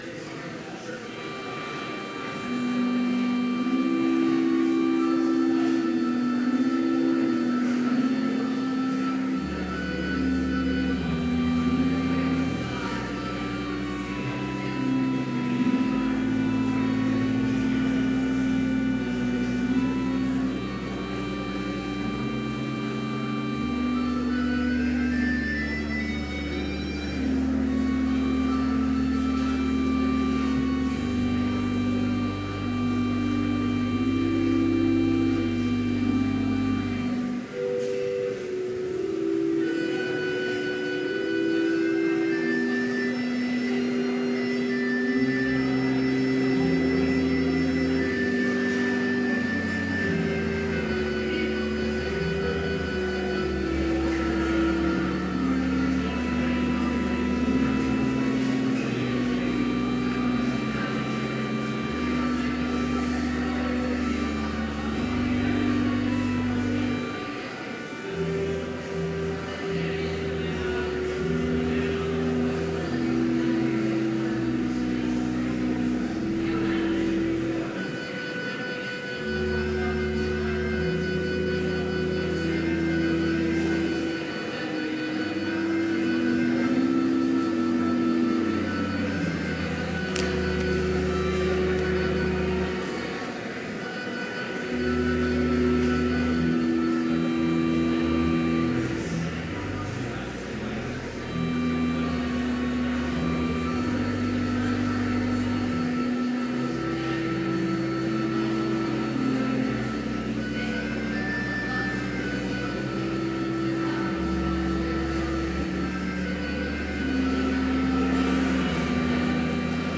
Complete service audio for Chapel - October 17, 2017